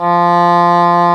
Index of /90_sSampleCDs/Roland LCDP04 Orchestral Winds/WND_English Horn/WND_Eng Horn 2